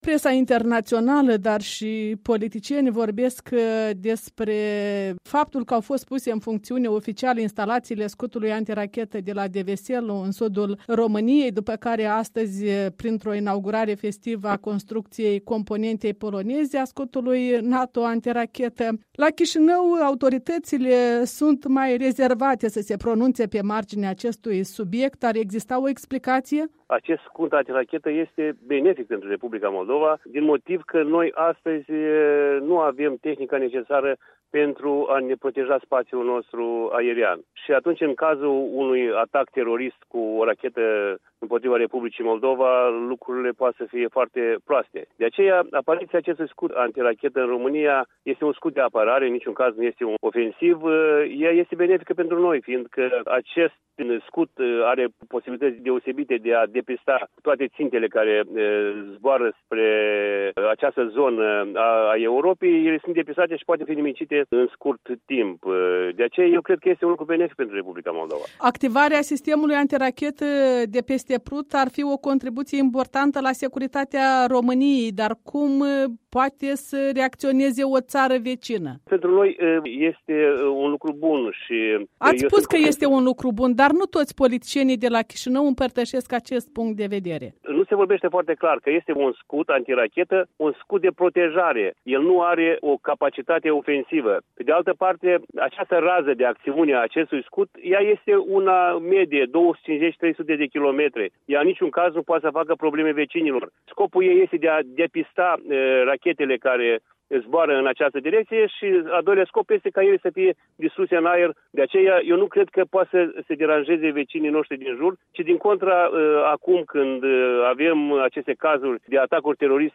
Interviu cu șeful comisiei parlamentare pentru securitate națională, apărare și ordine publică de la Chșținău.